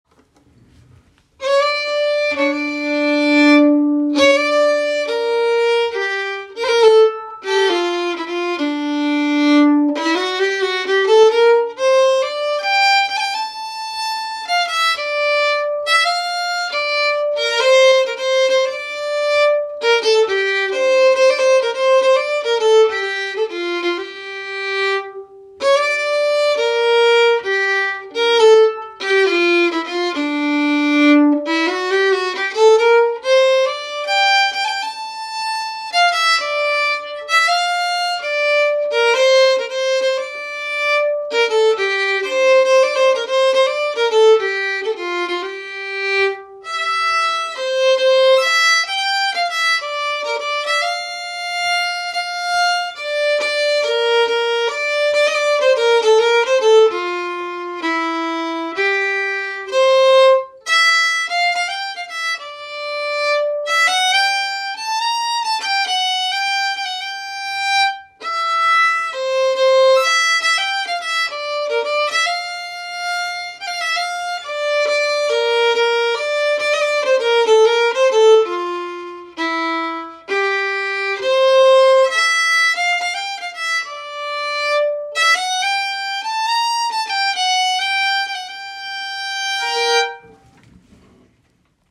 Begrundan är en polska komponerad av Hans Kennemark som del i ett Requiem ”Nu är en dag framliden”.
Melodi Långsam: Download